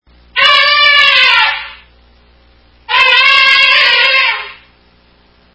African elephant
elephant.mp3